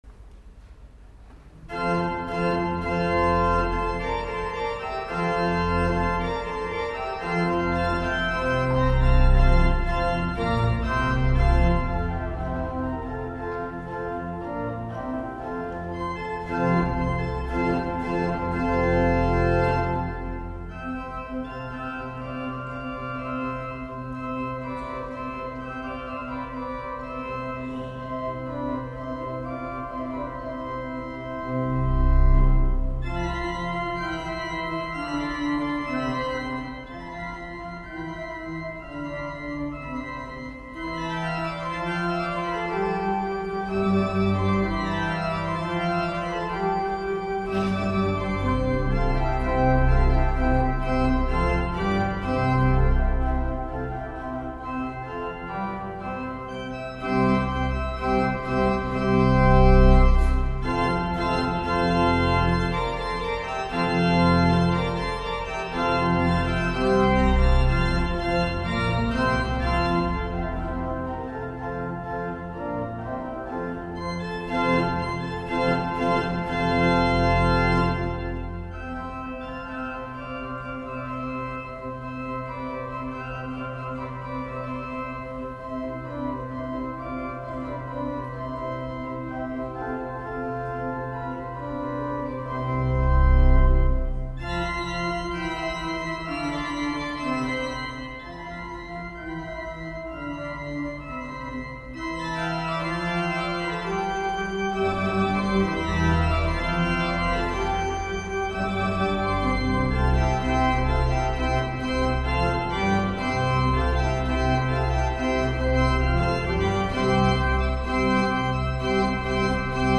Sonata per organo in fa maggiore di Andrea Luchesi
Dal concerto del 22 novembre 2017